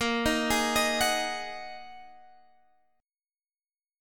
Bb7sus4#5 Chord